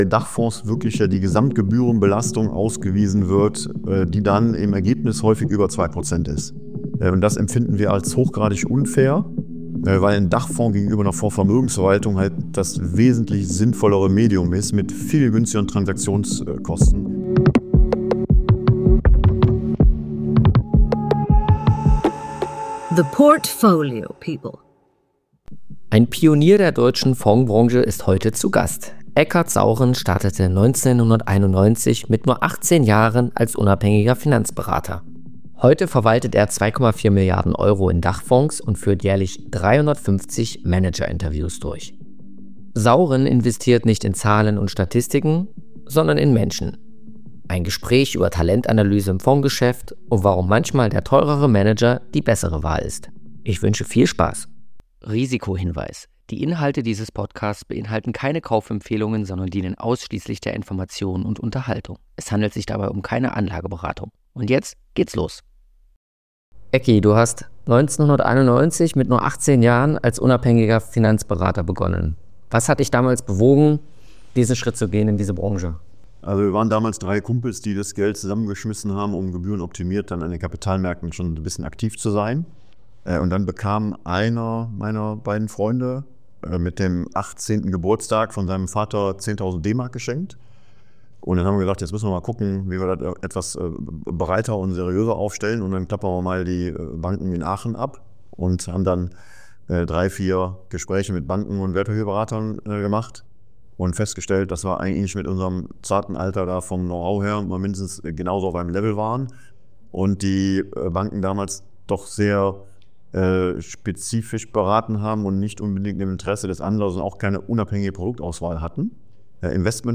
Ein intensives Gespräch über: * Die Kunst der Manager-Analyse * Die wahren Kosten von Qualität bei Fonds * Risikomanagement in Krisenzeiten * Die Zukunft aktiver Fonds * Seine Rolle beim 1. FC Köln Mehr